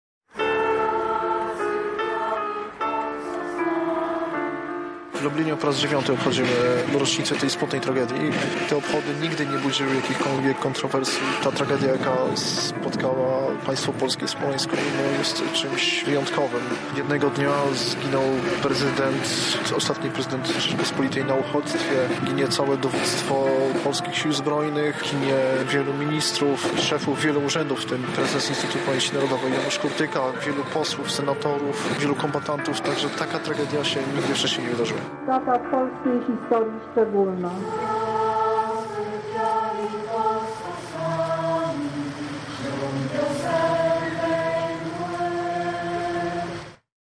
Minęło 9 lat od katastrofy smoleńskiej. Z tej okazji odbyły się uroczystości upamiętniające na Placu Litewskim